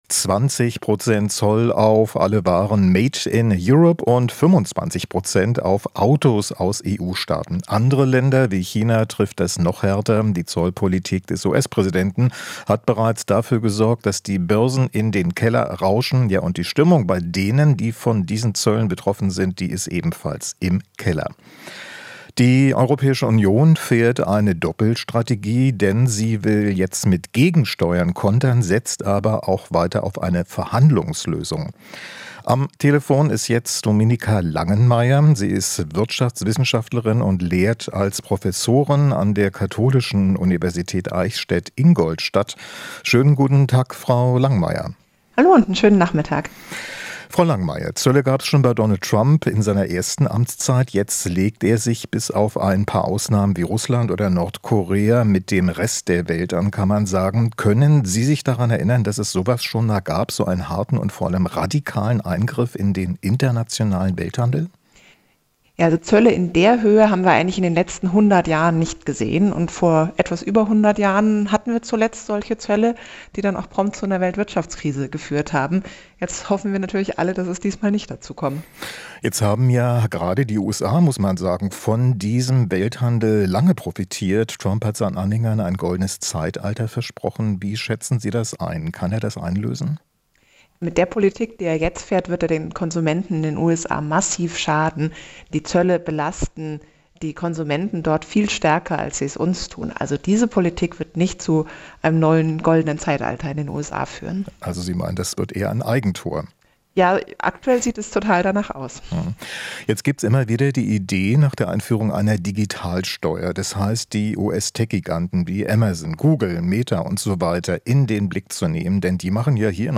Interview - Ökonomin zu US-Zöllen: Digitalsteuern keine einfache Lösung